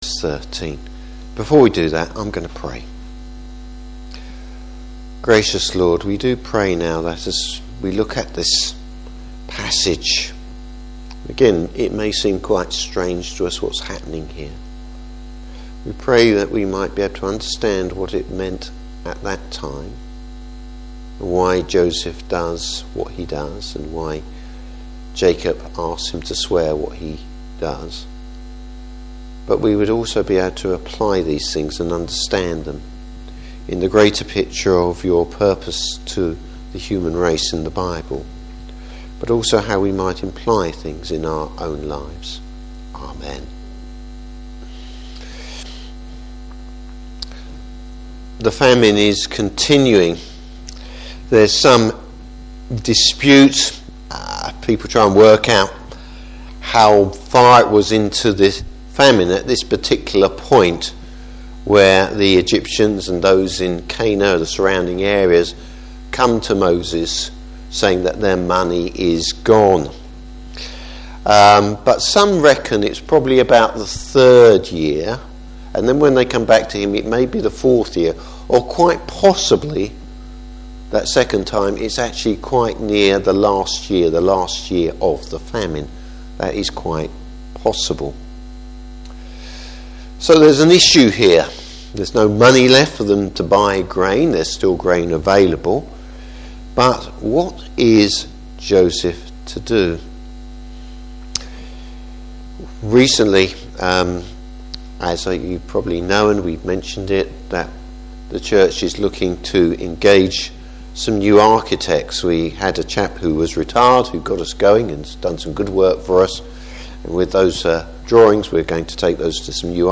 Service Type: Evening Service How God works in worlds history.